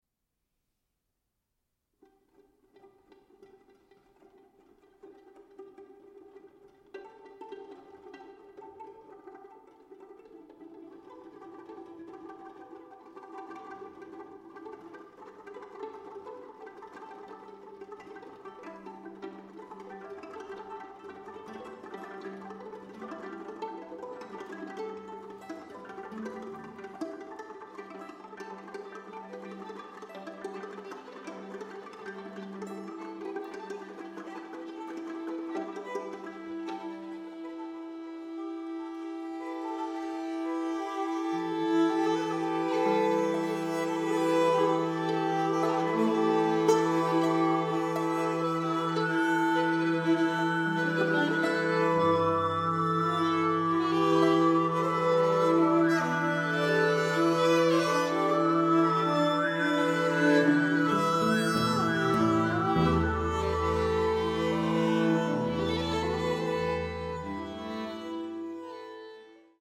voice
alto saxophone
violin
viola